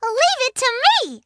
Voice clip from Mario & Sonic at the Sochi 2014 Olympic Winter Games